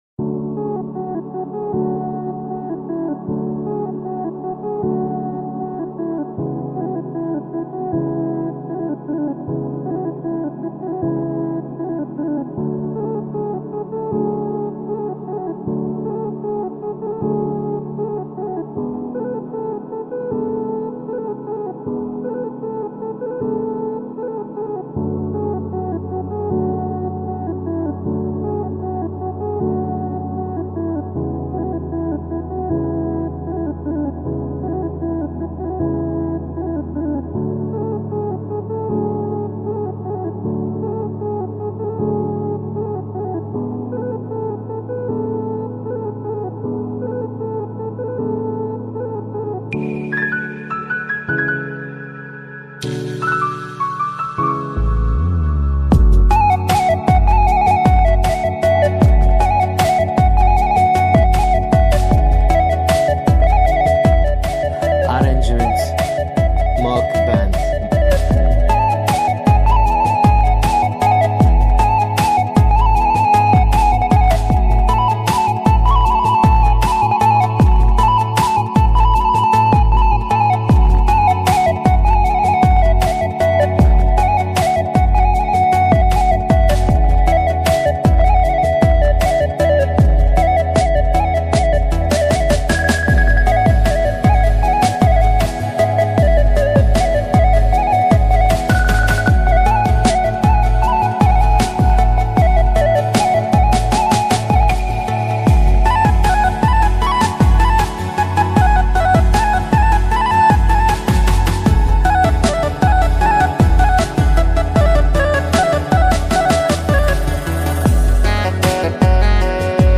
آهنگ بیکلام
آهنگ غمگین
Sad Music